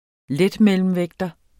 Udtale [ ˈlεdmεləmˌvεgdʌ ]